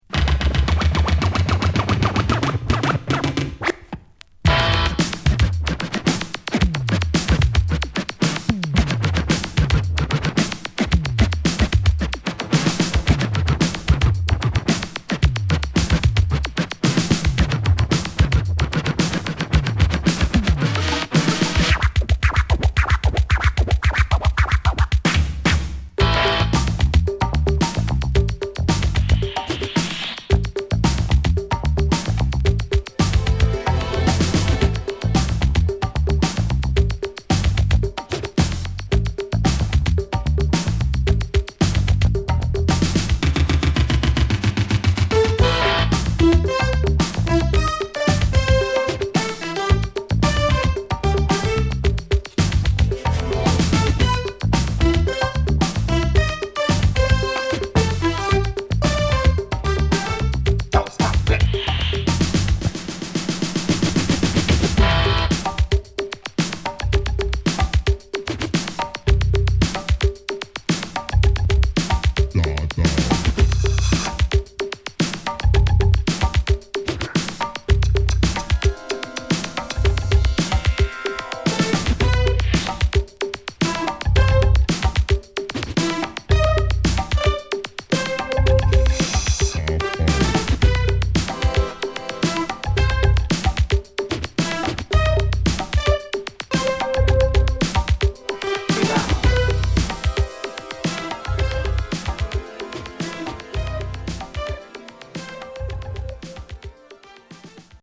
エレクトロ〜オールド・スクールクラシック！